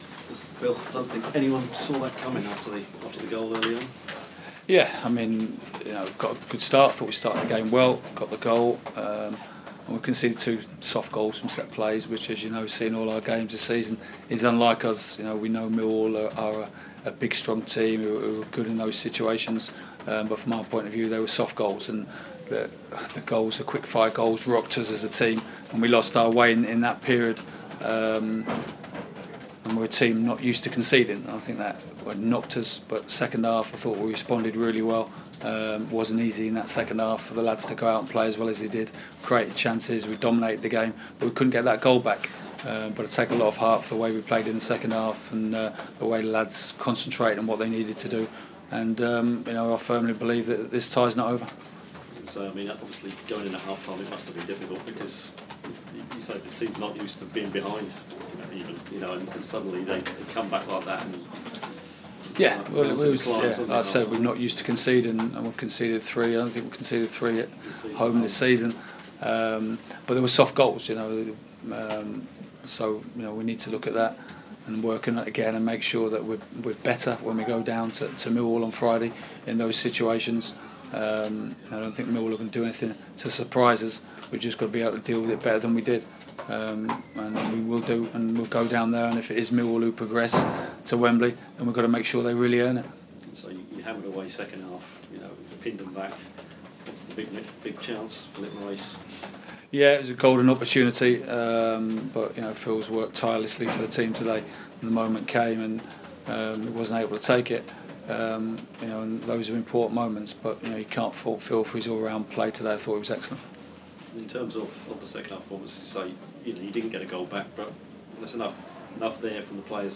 The Bantams trail Millwall after a tough game at Valley Parade 3-1 and the manager of Bradford Phil Parkinson spoke to the press after the first leg of the playoffs.